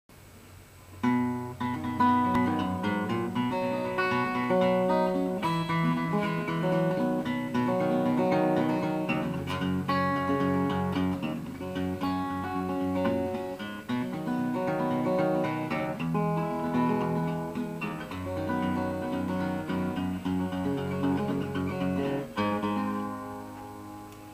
It seems to have no limit as far as headroom, it just gets louder, yet it handles very soft fingerstyle well.
With single notes and two note chords, you hear a wonderful array of pleasing overtones, yet they don't interfere with clarity when playing full chord or fingerstyle.
It is balanced across the strings.
(Highly customized Size 1 guitar in European spruce and Pacific madrone (Arbutus menziesii))